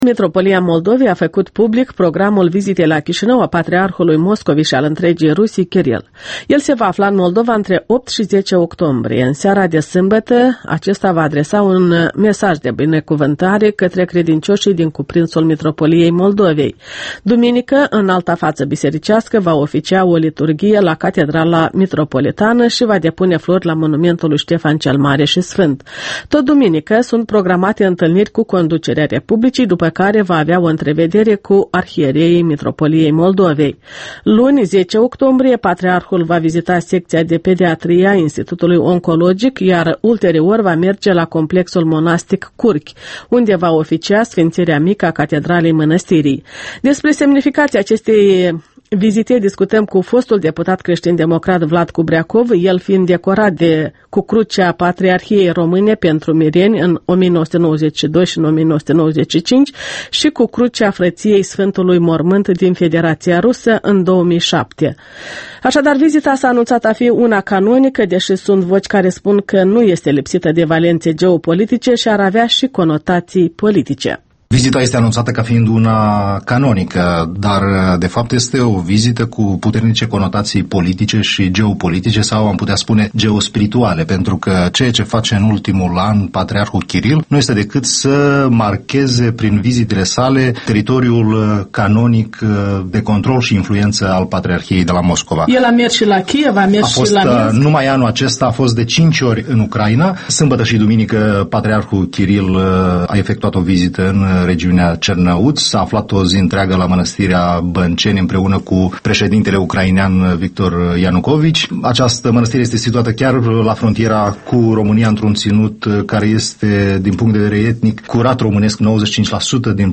Interviul dimineții la Europa Liberă: cu Vlad Cubreacov despre semnificația unei vizite patriarhale